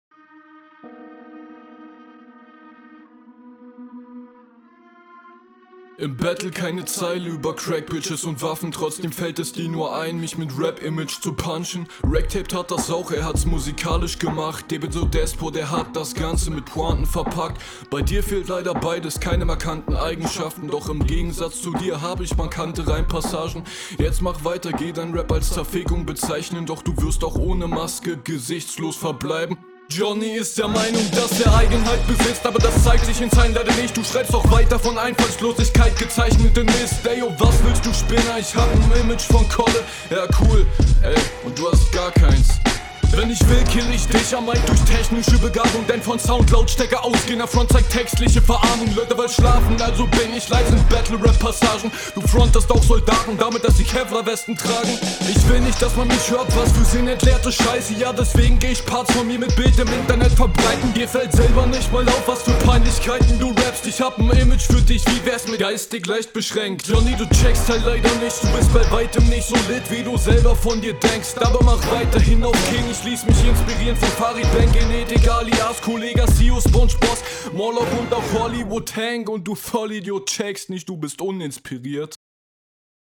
Hier fehlt mir die Power, deine Doubles sitzen nicht onpoint.